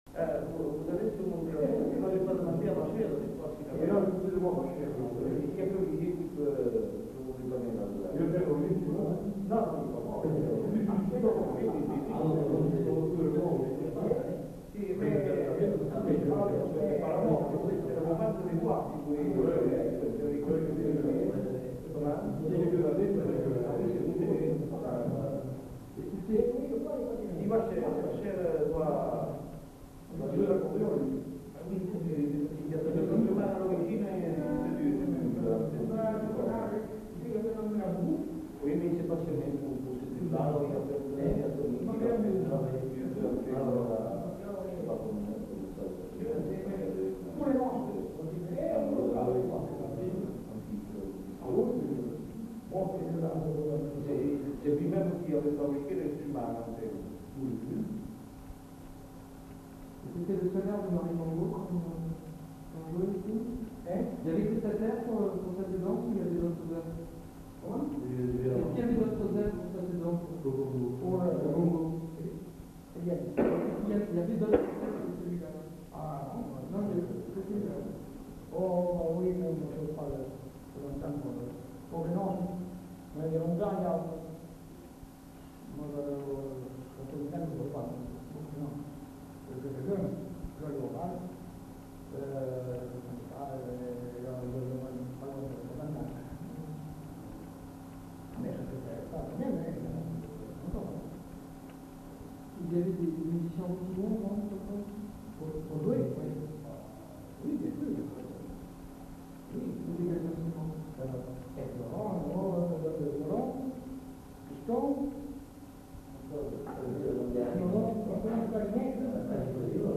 Lieu : Moncaut
Genre : témoignage thématique